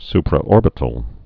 (sprə-ôrbĭ-tl)